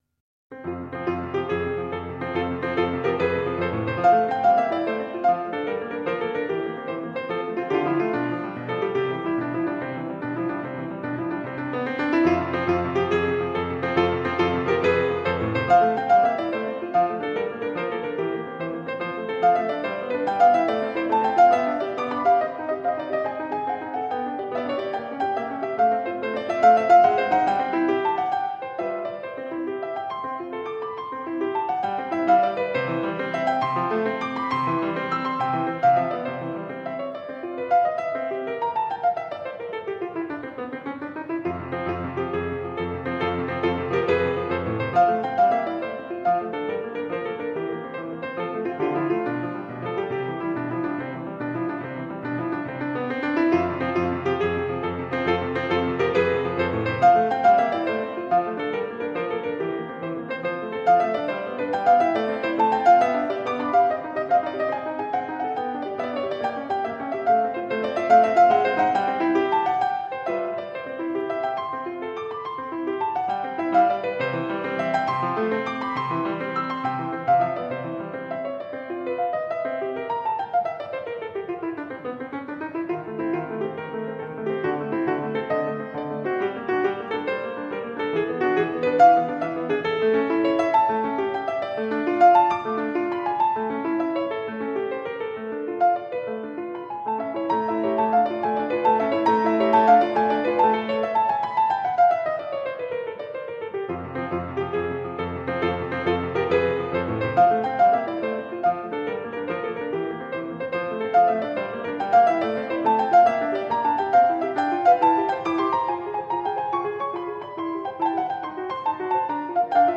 Classical piano sonatinas for students.
Classical, Classical Period, Romantic Era, Instrumental
Classical Piano